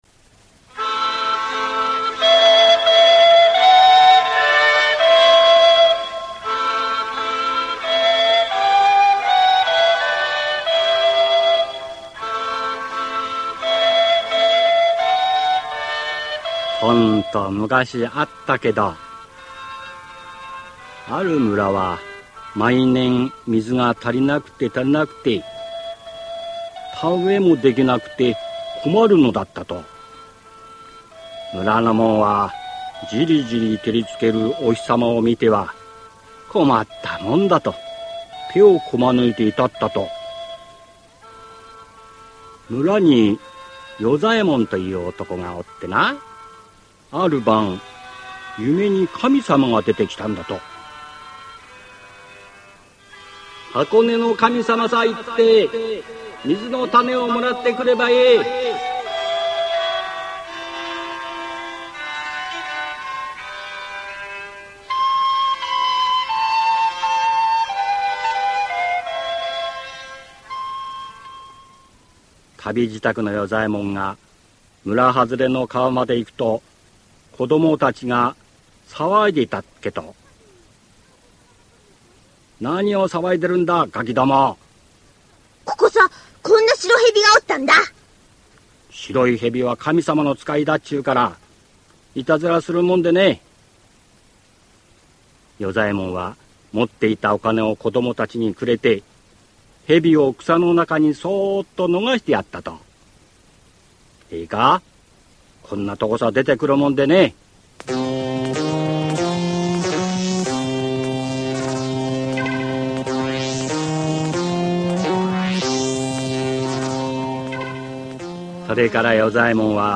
[オーディオブック] 水の種